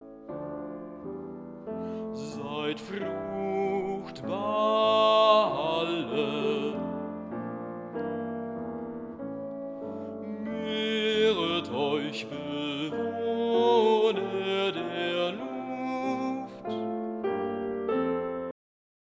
More Mics on the stand :-)
Hier habe ich schnell noch mal das Blumlein rausgebounct, wie ich es gehörrichtig finde. Mit dem GHZ Mid-Side Plugin habe ich es etwas nach links geschoben und mit dem eingebauten Tilt EQ spiegelsymmetrisch zwischen 200 Hz und 0 Hz die Bassschwäche der Achtcharakterisitk kompensiert.